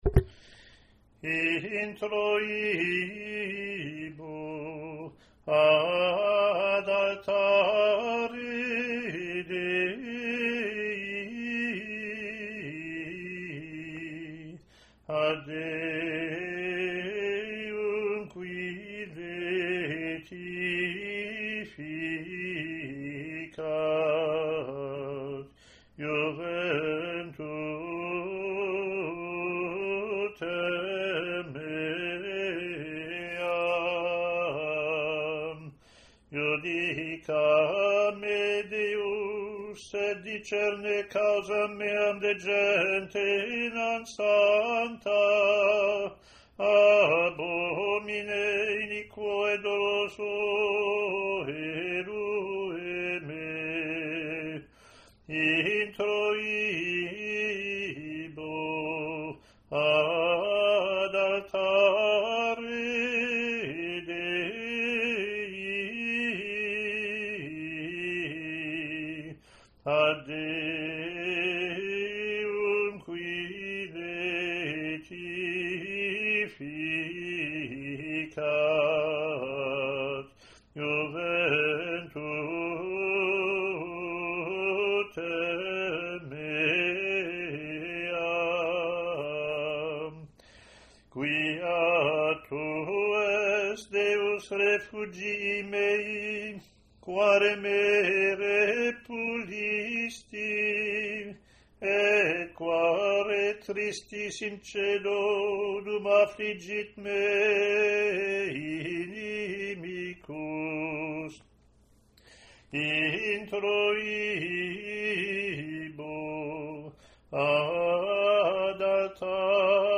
Year AC Latin antiphon+ Verse,